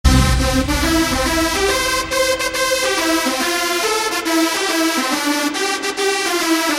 Moving Train Sound Effect Free Download
Moving Train